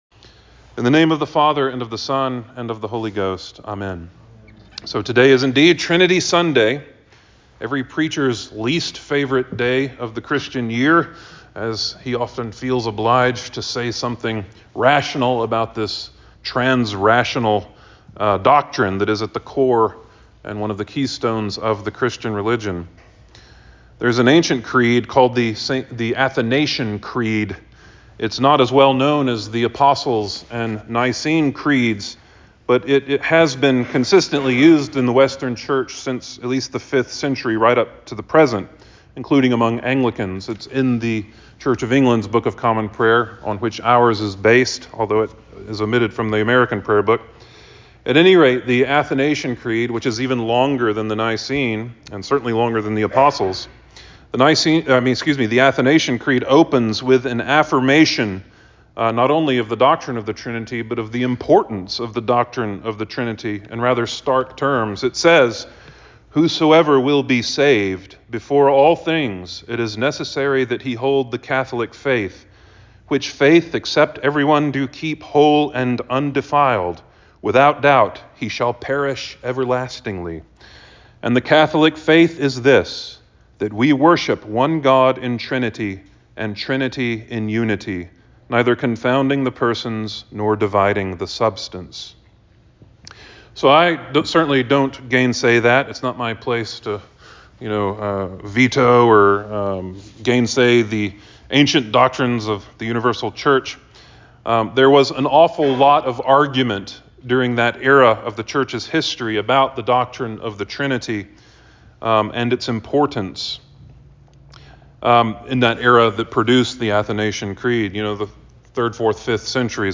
Sermon for Trinity Sunday 5.26.24